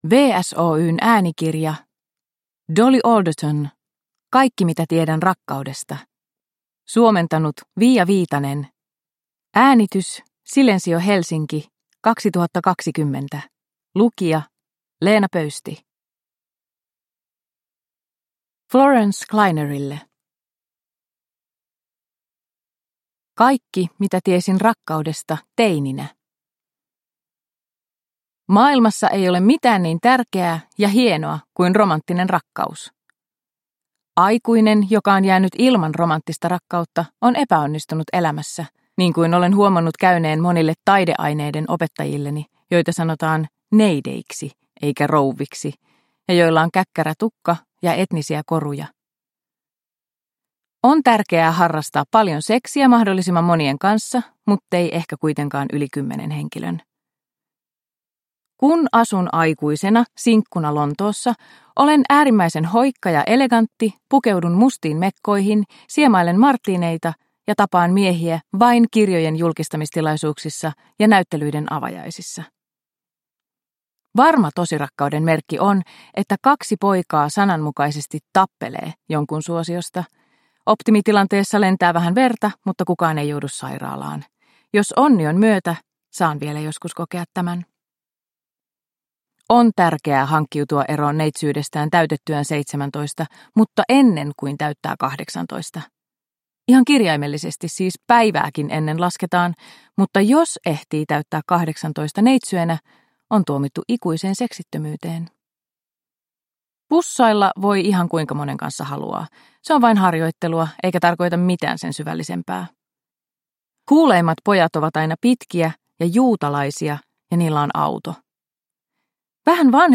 Kaikki mitä tiedän rakkaudesta – Ljudbok – Laddas ner